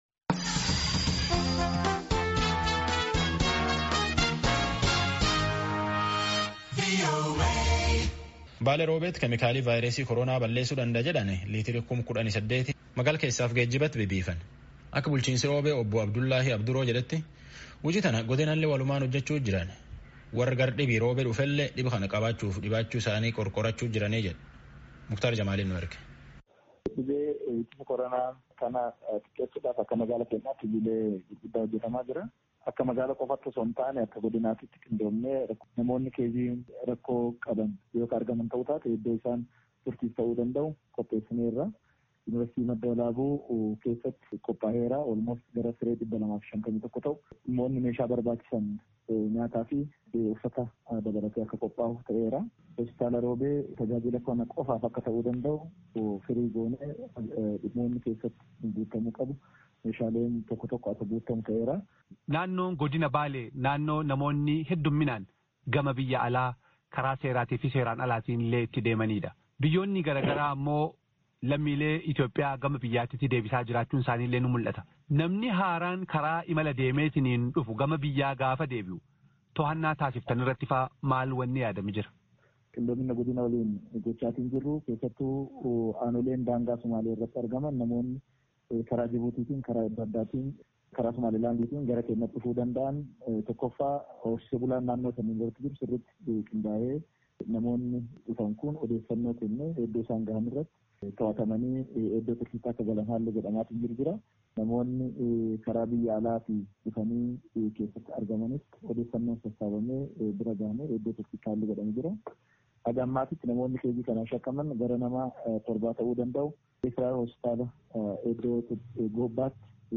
Kantibaa Magaala Robee, Obbo Abduljaliil Abduroo